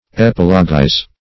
\ep"i*lo*guize\